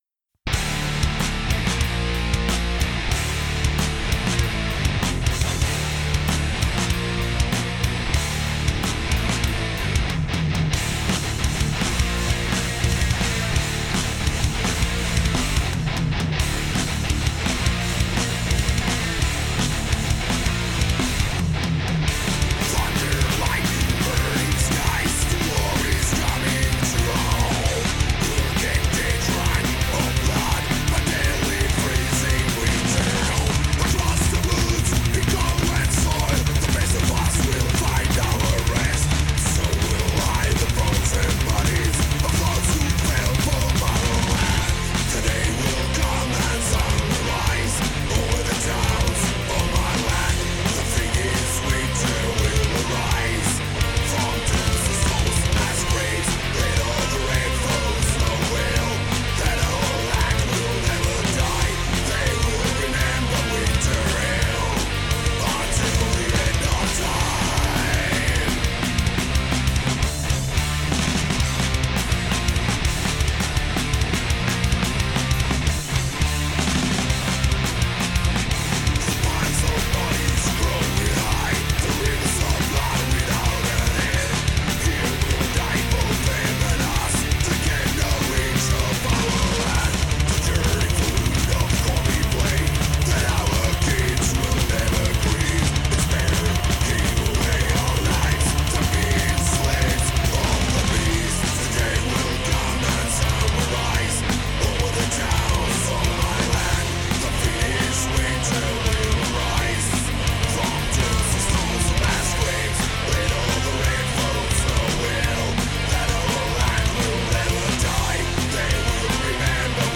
Gastgesang